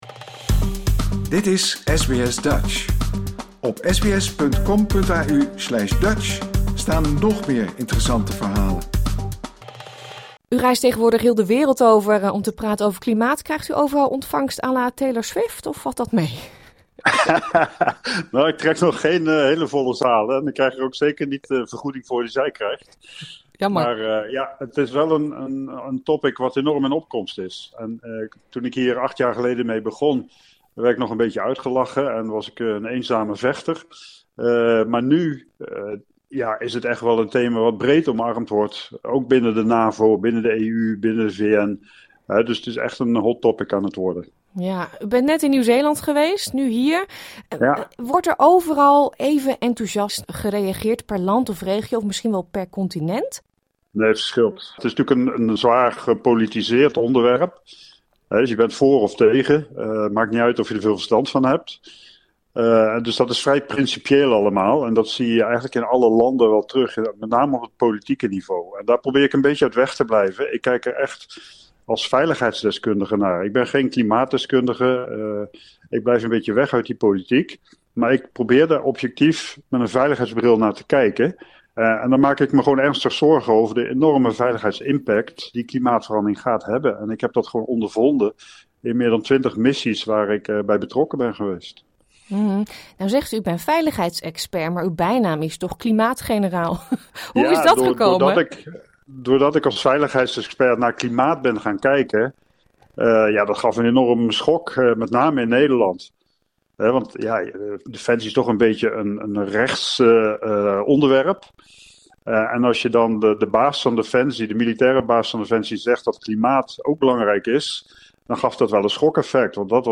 Wij spraken de klimaatgeneraal ruim een jaar geleden voor zijn toespraak in de National Press Club in Canberra.